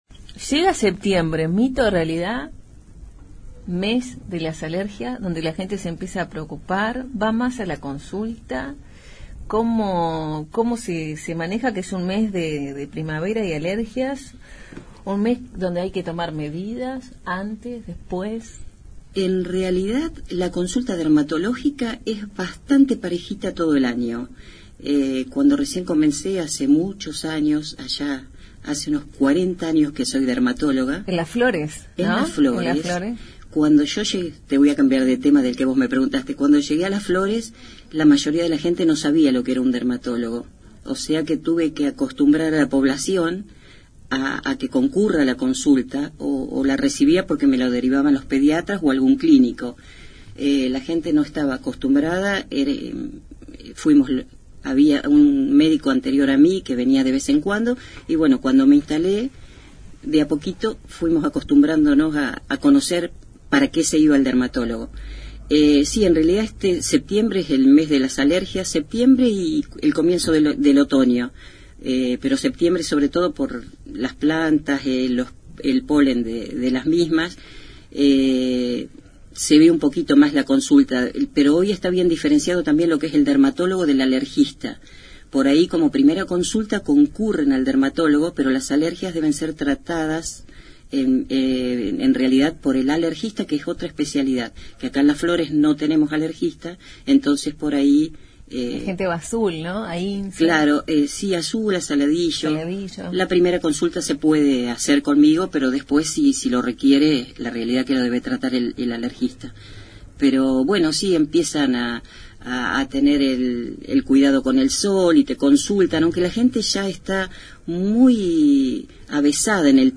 Durante la entrevista, además de conocer las últimas tendencias para la piel, la médica contó parte de su historia de vida hasta convertirse en una de las profesionales de la salud más queridas de la ciudad.